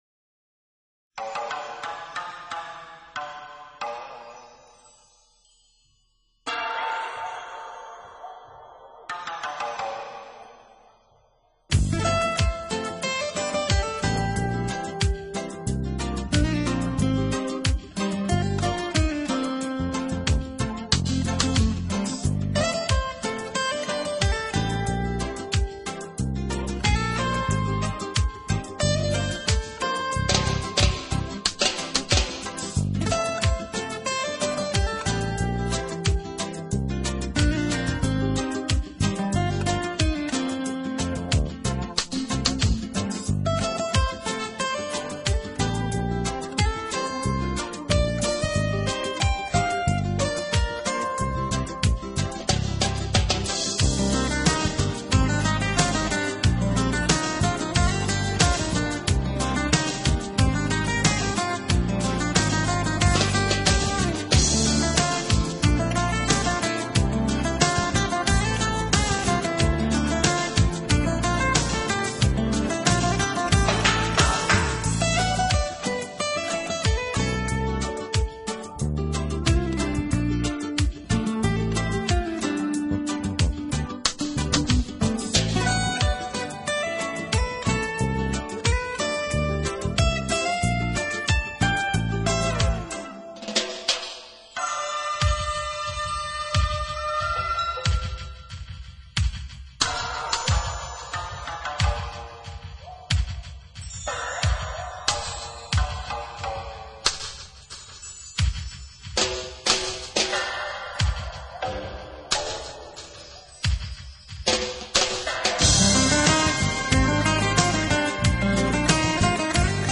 音乐类型：Jazz 爵士
音乐风格：New Age,Smooth Jazz,Contemporary,Instrumental